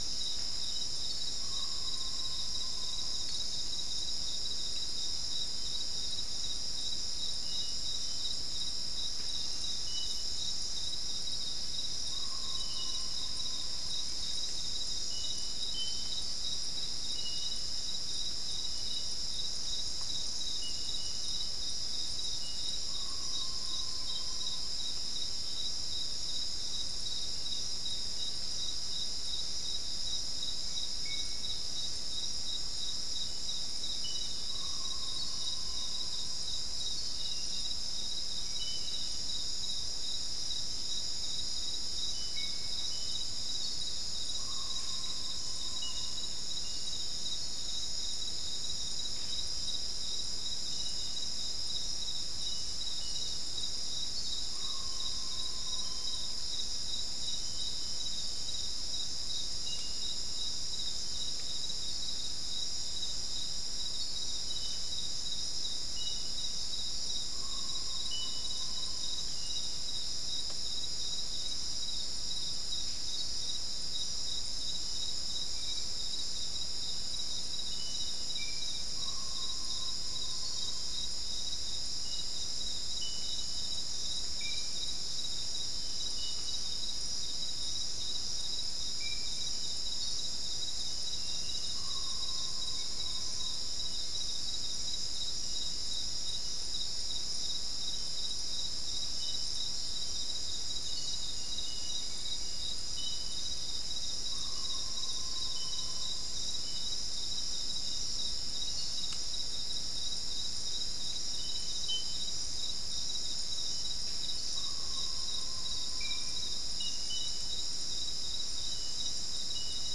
Non-specimen recording: Soundscape Recording Location: South America: Guyana: Kabocalli: 3
Recorder: SM3